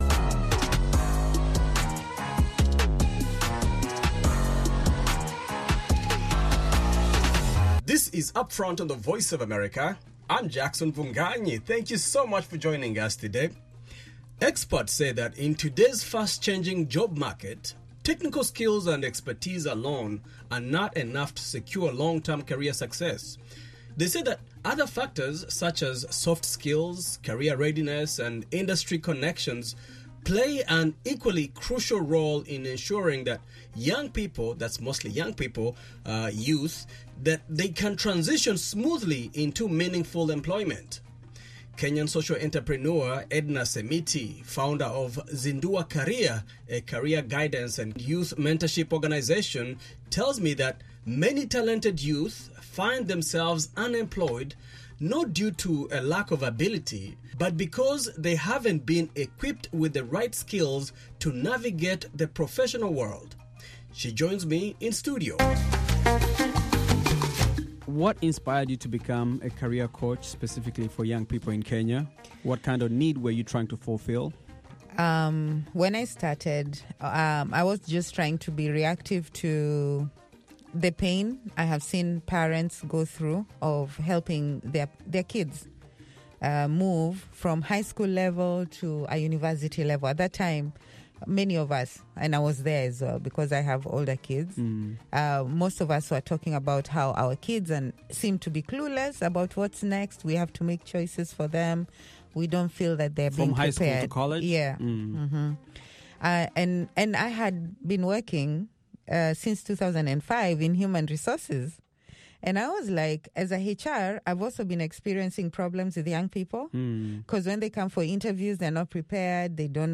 In this interview, she shares insights on empowering students to align their ambitions with real opportunities in today’s global economy.